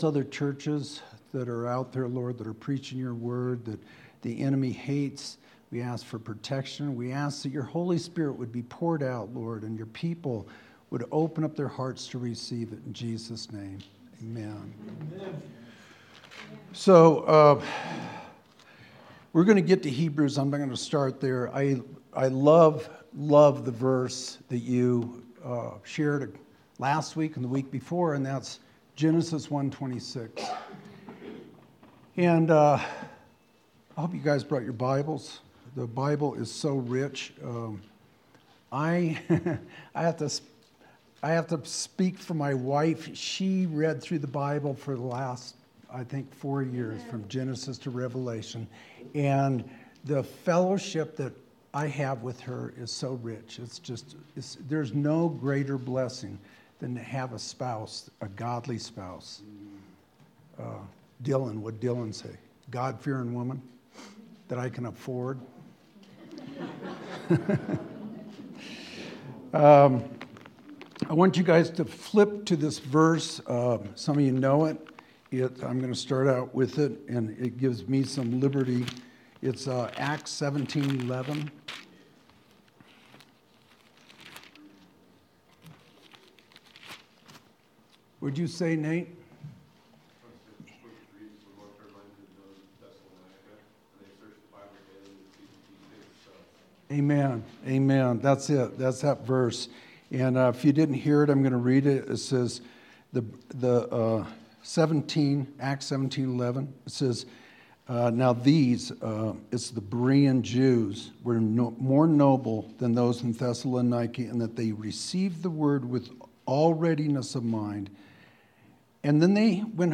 January 16th, 2022 Sermon